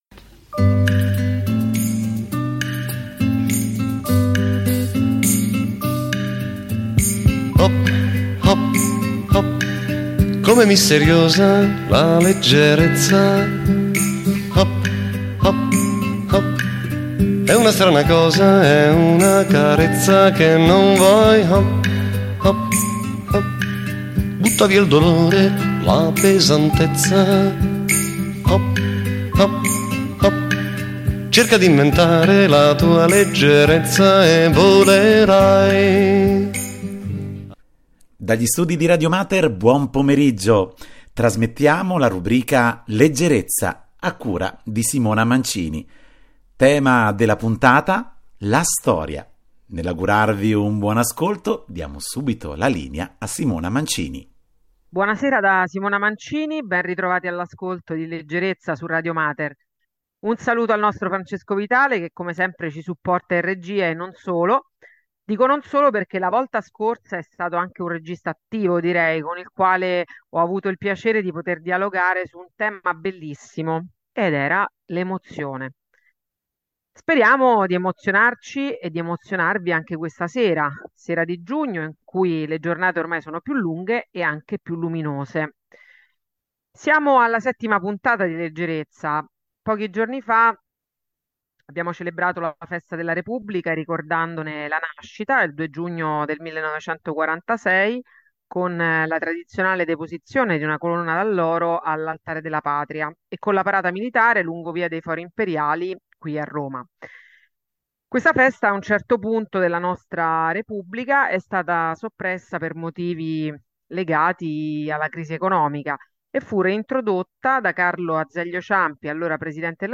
E’ il percorso di costruzione della nostra identità, quindi anche fascino, scoperta e meraviglia, persino metafora come espresso dalla lettura della poesia Stupor mundi dedicata a Federico II.